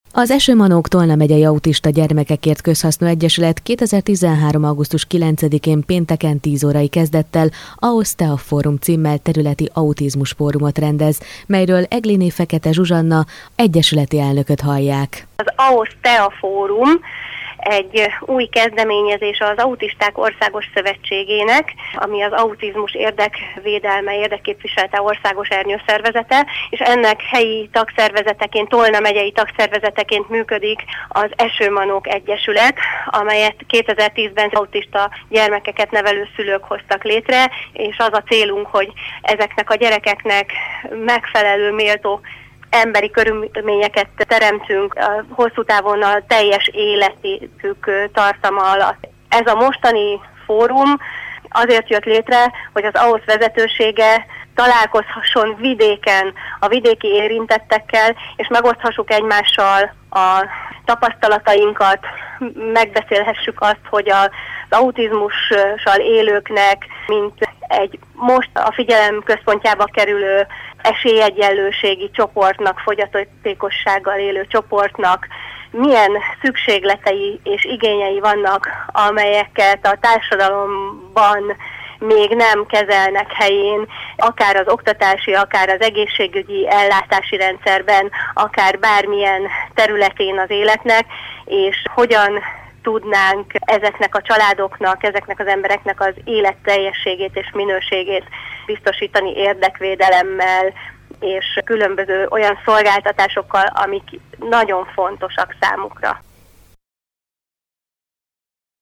telefonos_interju_antritt.mp3